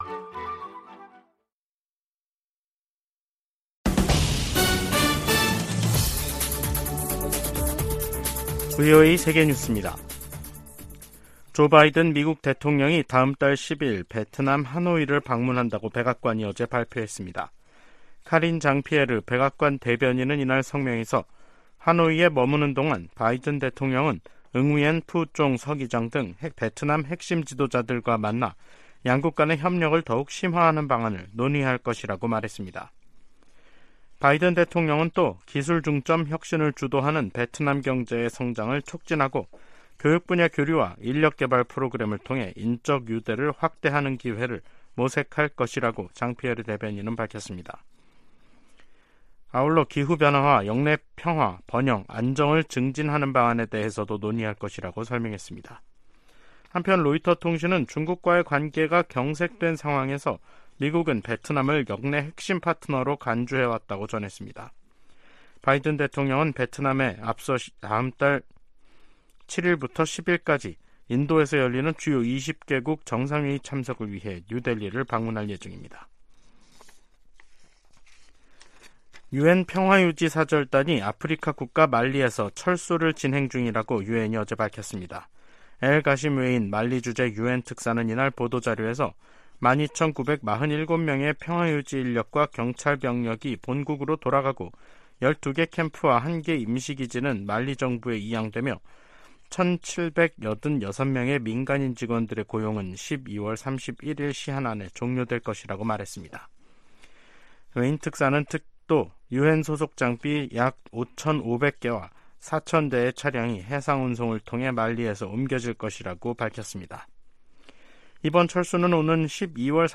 VOA 한국어 간판 뉴스 프로그램 '뉴스 투데이', 2023년 8월 29일 2부 방송입니다. 제네바에서 열린 유엔 회의에서 미국은 북한의 위성 발사가 전 세계에 대한 위협이라고 비판했습니다. 북한이 위성 발사 실패시 미국과 한국이 잔해를 수거 분석할 것을 우려해 의도적으로 로켓을 폭파시켰을 가능성이 있다고 전문가들이 분석했습니다. 김정은 북한 국무위원장이 미한일 정상들을 비난하면서 3국 합동훈련 정례화 등 합의에 경계심을 드러냈습니다.